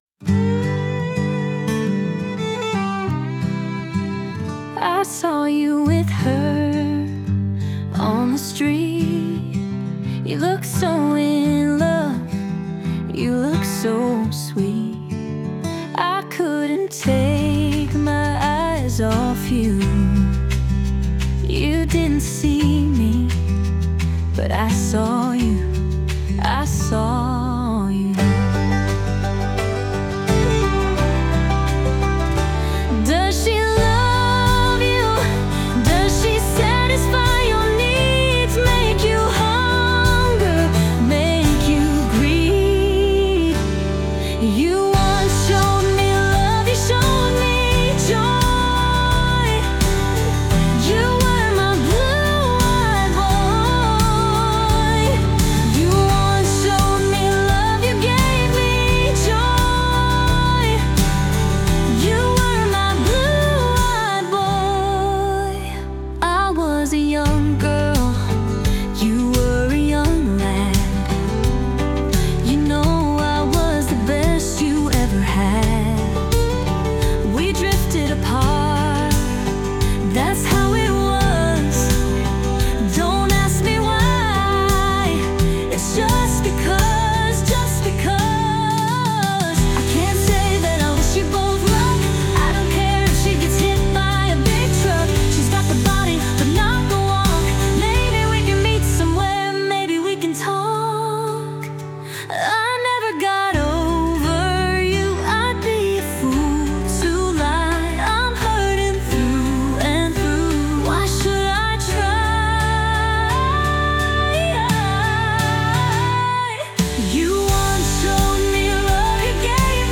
female led Country